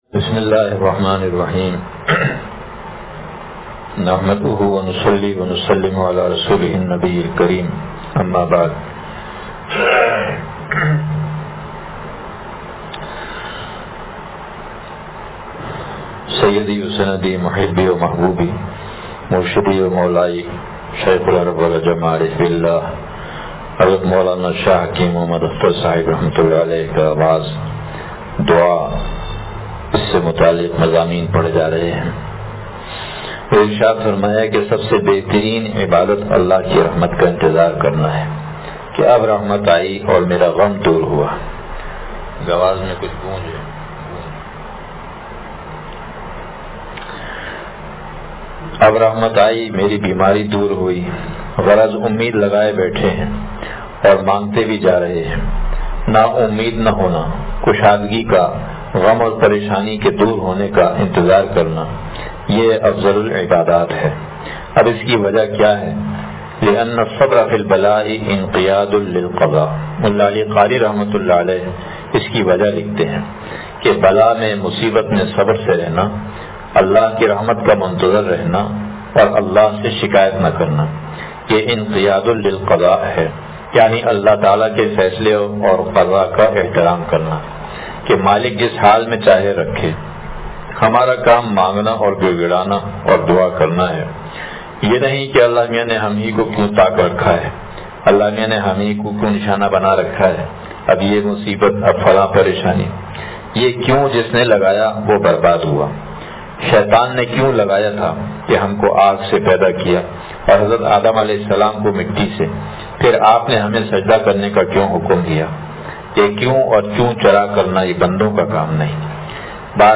شیخ العرب والعجم عارف باللہ مجدد زمانہ حضرت والا رحمتہ اللہ علیہ کا وعظ دعا سے پڑھا